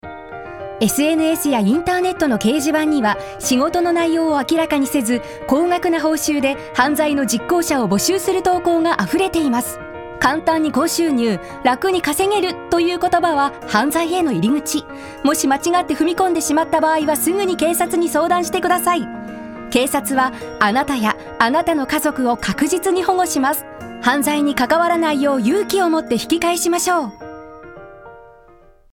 声を聴く Voice Sample
5.文字数多めの声～呼びかけ